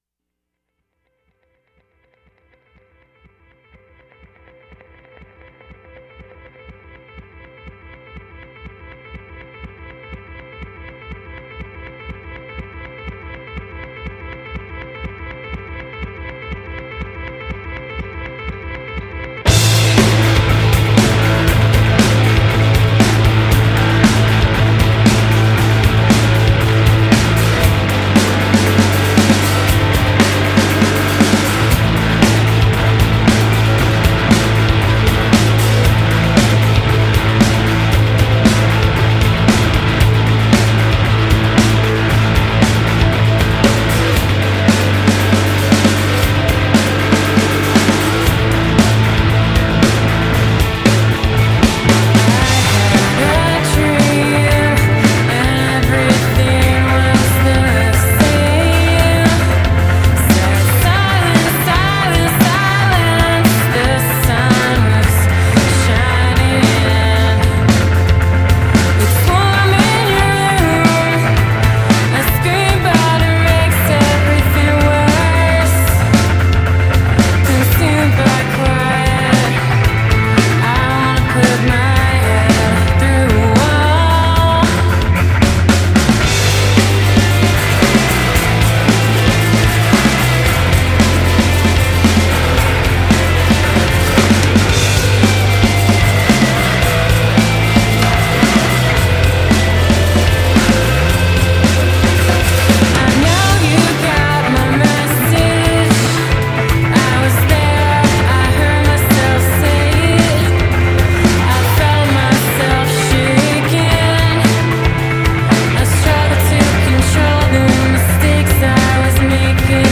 four-piece Brooklyn noise pop band